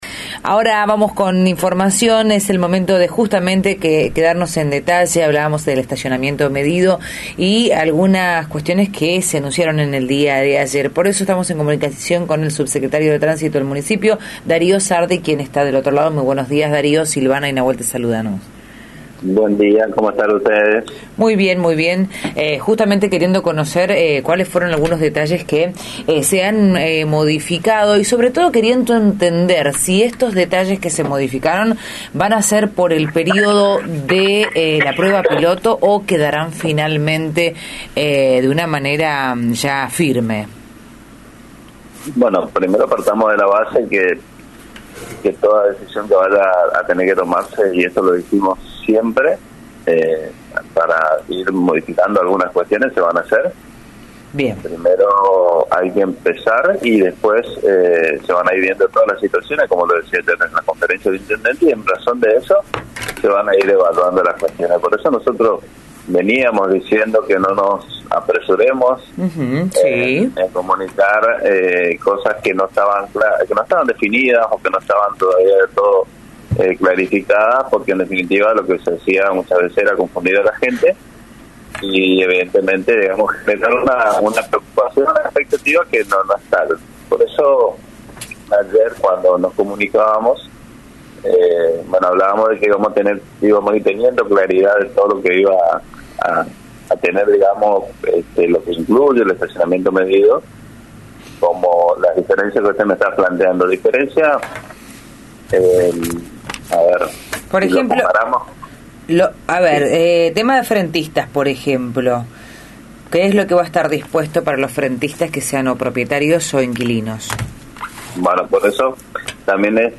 Darío Sardi, titulas de Tránsito y Transporte de Resistencia, comentó al aire de Radio Facundo Quiroga que la implementación del SIDEM en la ciudad iniciará el 17 de febrero extendiéndose hasta el 31 de marzo.